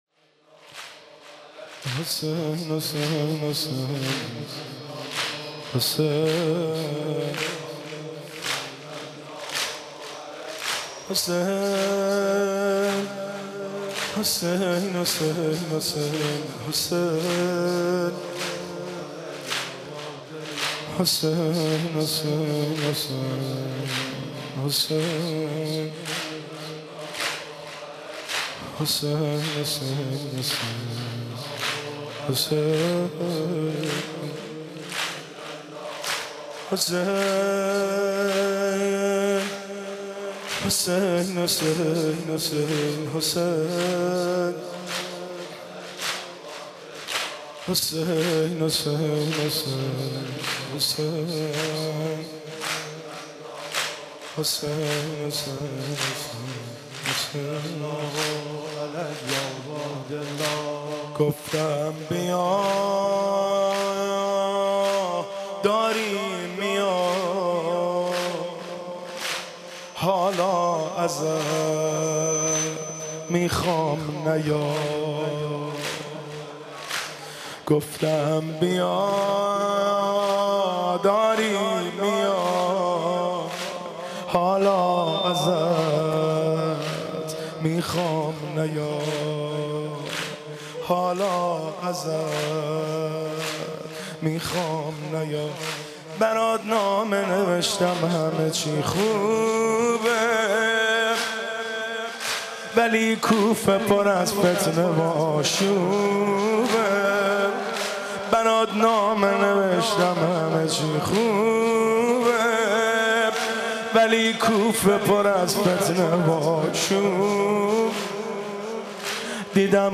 که در هیئت بین الحرمین تهران اجرا شده است